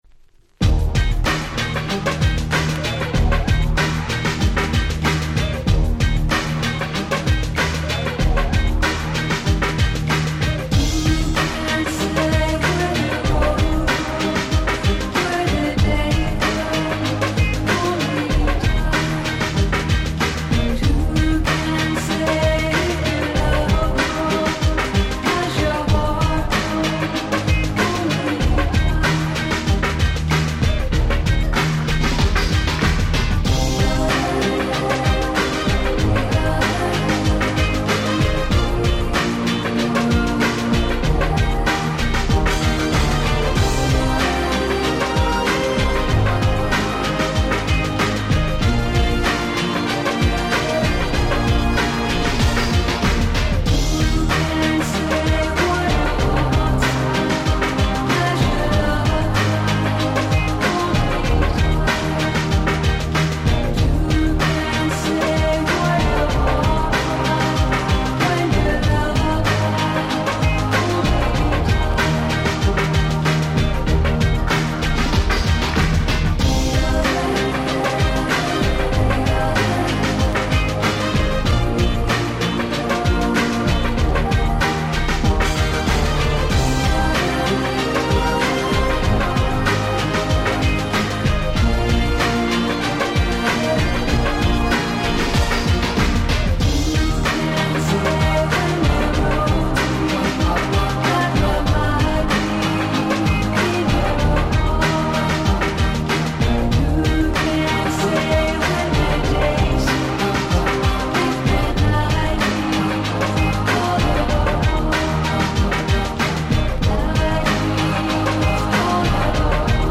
Ground Beatのリズムを下に敷くだけであら不思議、壮大感3割り増しなのです！！